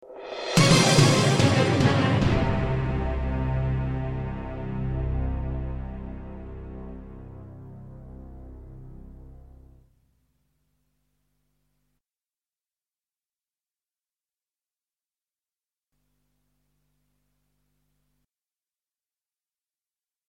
Hudba